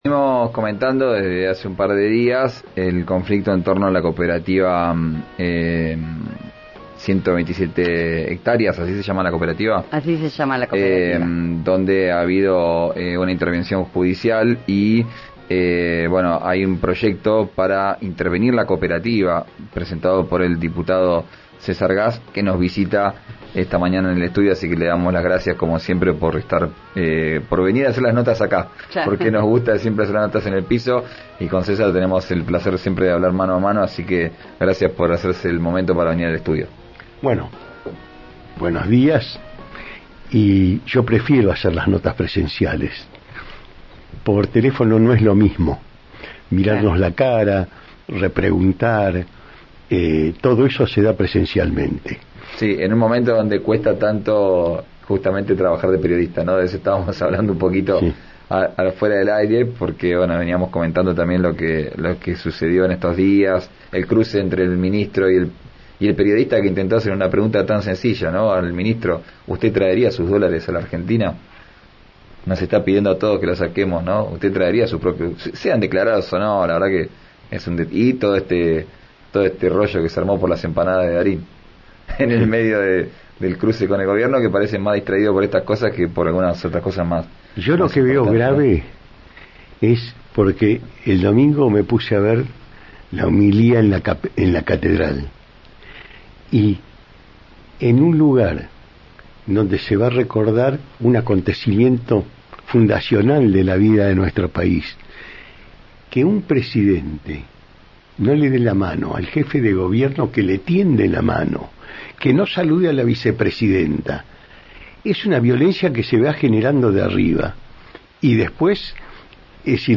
Escuchá al diputado César Gass en RÍO NEGRO RADIO: